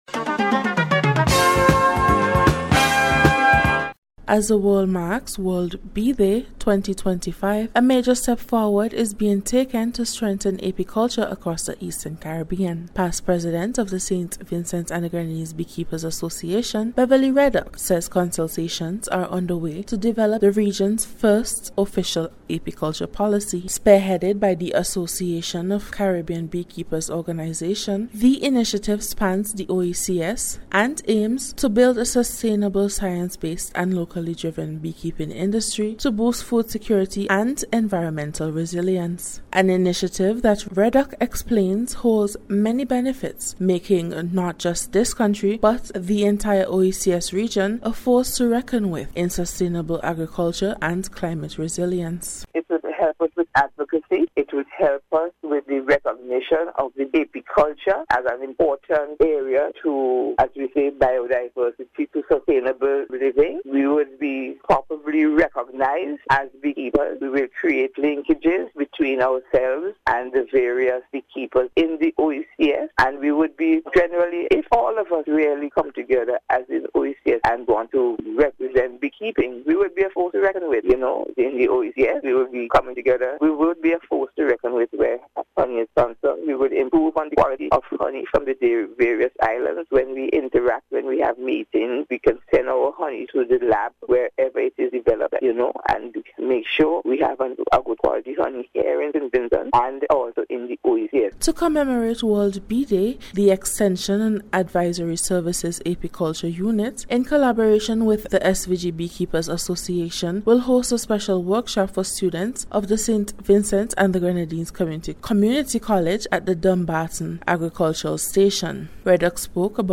NBC’s Special Report- Tuesday 20th May,2025